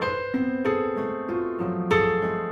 Index of /musicradar/gangster-sting-samples/95bpm Loops
GS_Piano_95-C2.wav